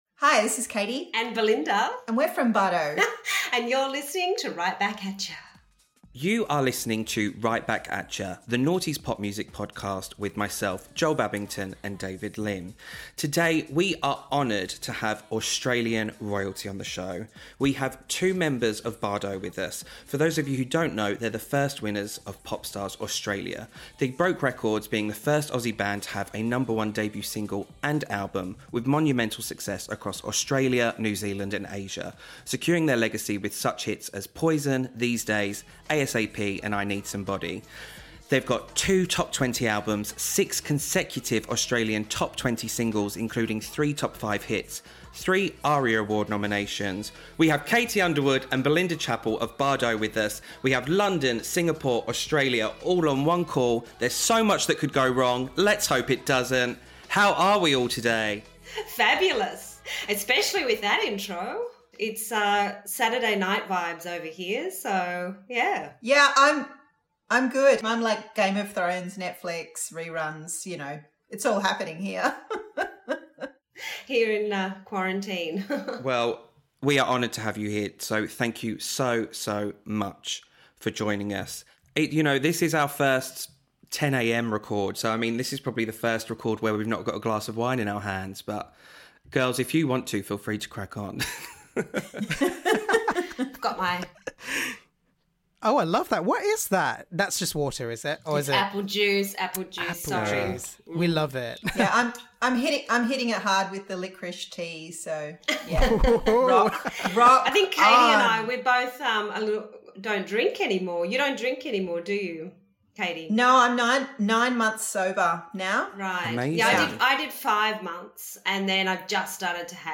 Bardot interview with Belinda Chapple & Katie Underwood